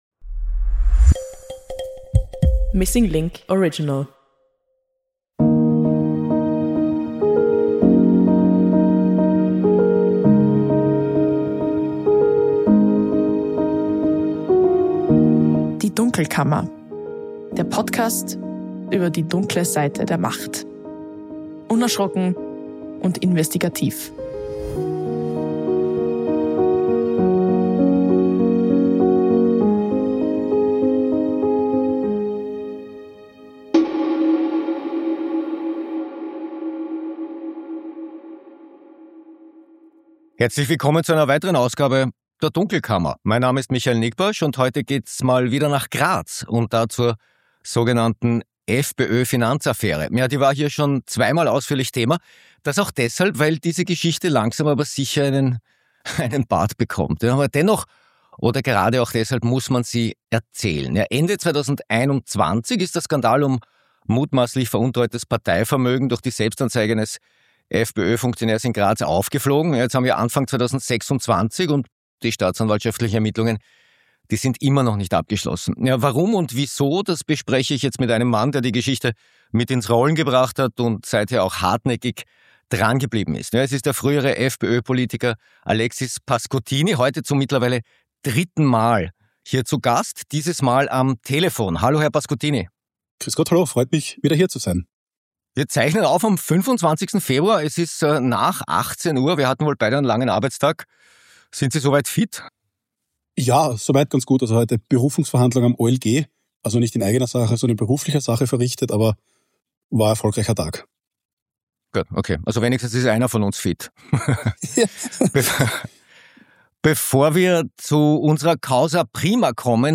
In der 296. Ausgabe der Dunkelkammer ist wieder Alexis Pascuttini zu Gast. Der frühere FPÖ-Politiker engagiert sich seit Jahren für Aufklärung in der Finanzaffäre rund um mutmaßlich veruntreute Parteiförderungen der Grazer Stadtpartei. In den Ermittlungen der Staatsanwaltschaft Klagenfurt gibt es wichtige neue Erkenntnisse, Stichwort: Kontenauswertungen.